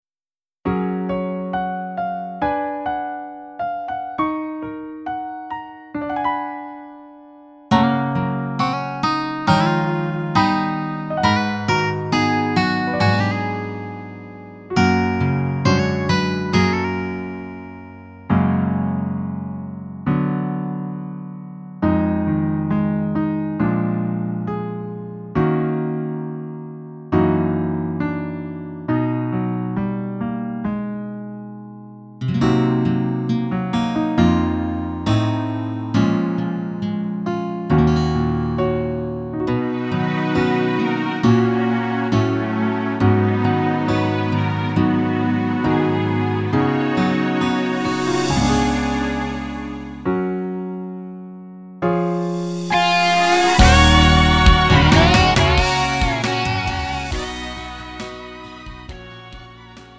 음정 -1키 3:39
장르 가요 구분 Lite MR
Lite MR은 저렴한 가격에 간단한 연습이나 취미용으로 활용할 수 있는 가벼운 반주입니다.